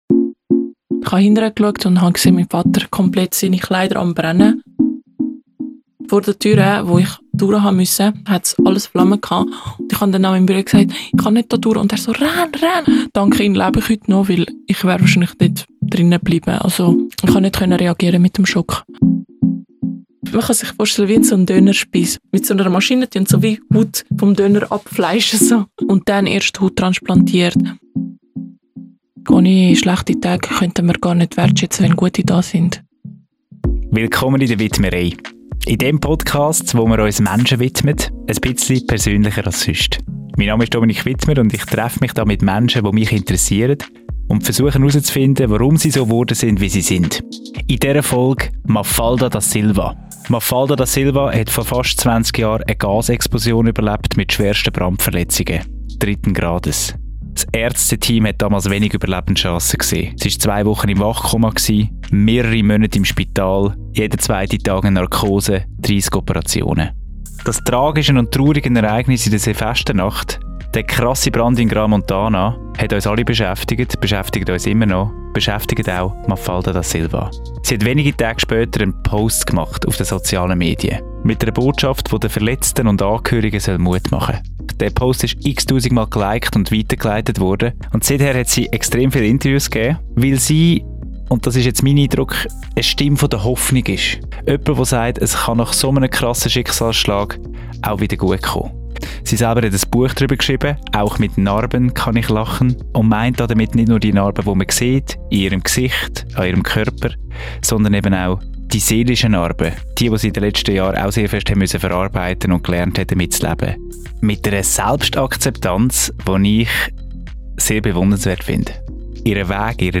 Ein Gespräch über Verletzlichkeit, Stärke und Hoffnung.